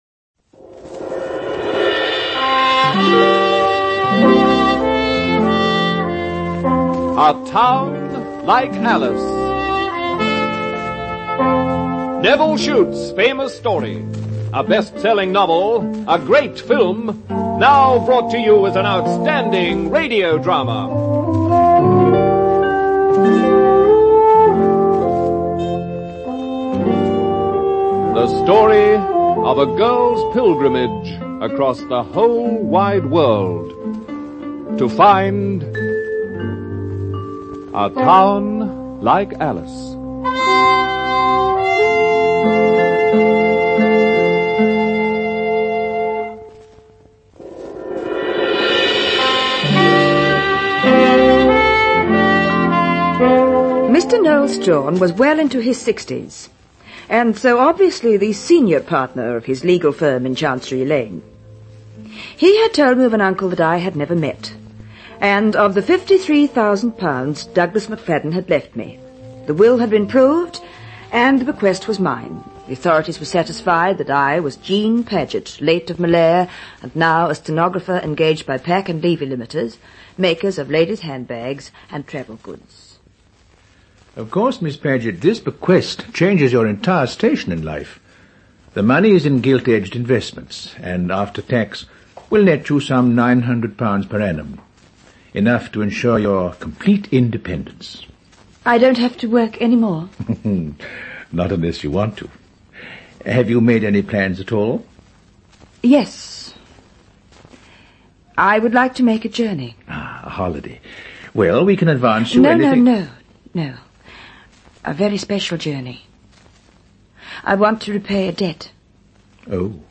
A Town Like Alice (Track 1) Mono